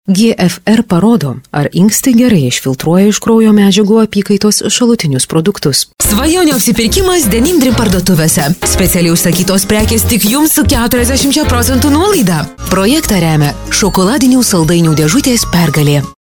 Lithuanian voice talent